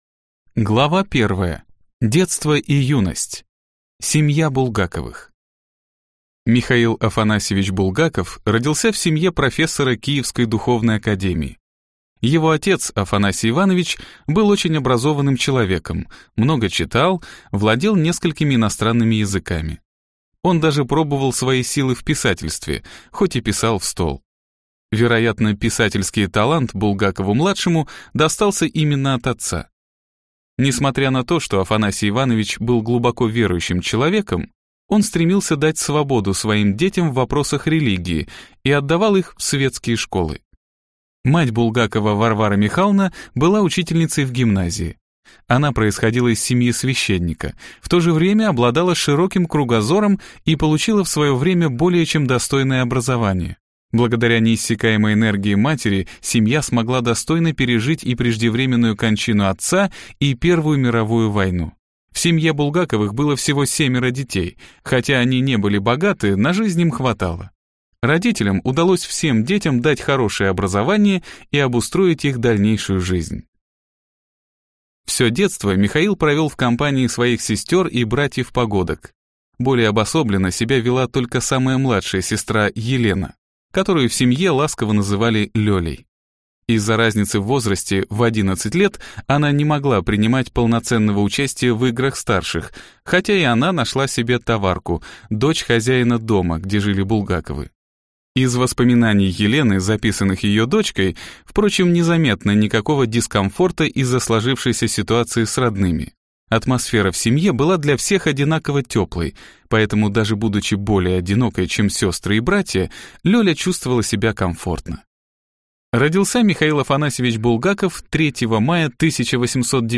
Аудиокнига Михаил Булгаков. Тайная жизнь Мастера | Библиотека аудиокниг